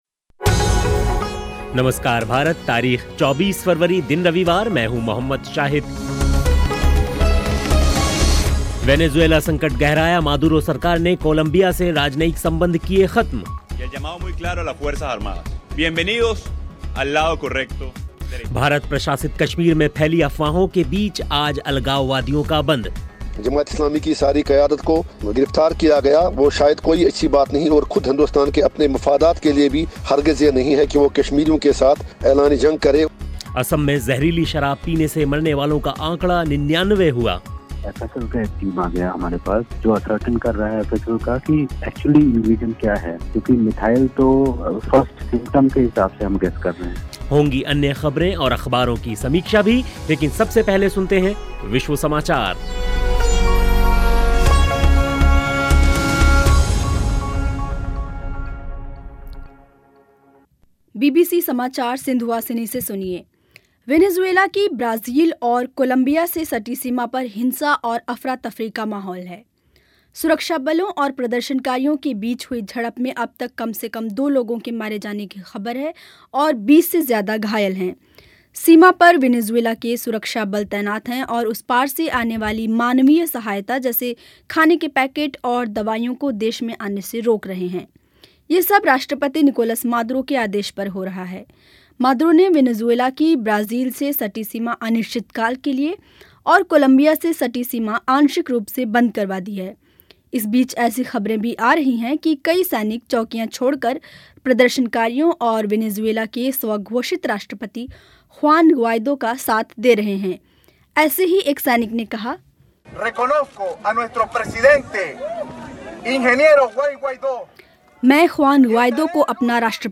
असम में ज़हरीली शराब पीने से मरने वालों का आंकड़ा 99 हुआ. होंगी अन्य ख़बरें और अख़बारों की समीक्षा भी लेकिन पहले विश्व समाचार सुनिए.